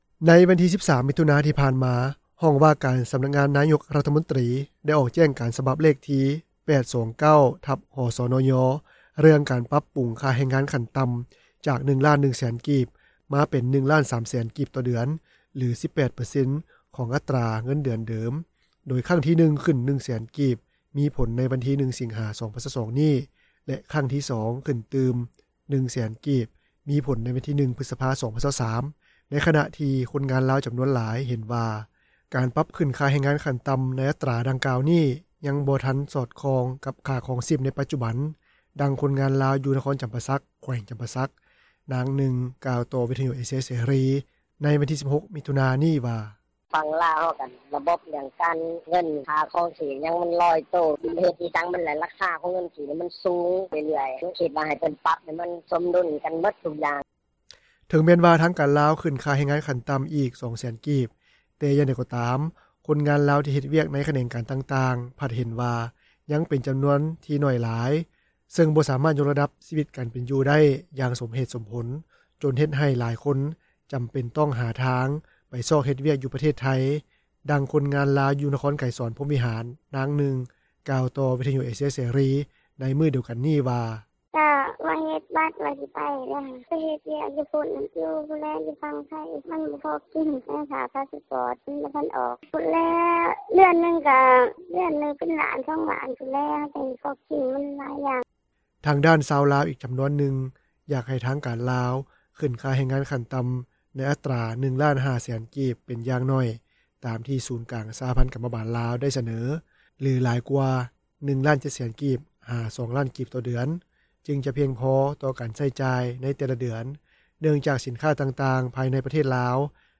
ດັ່ງຄົນງານລາວ ຢູ່ນະຄອນໄກສອນພົມວິຫານ ນາງນຶ່ງ ກ່າວຕໍ່ວິຍຸເອເຊັຽເສຣີ ໃນມື້ດຽວກັນນີ້ວ່າ:
ດັ່ງຜູ້ປະກອບການຮ້ານອາຫານ ຢູ່ນະຄອນໄກສອນພົມວິຫານ ນາງນຶ່ງ ກ່າວວ່າ: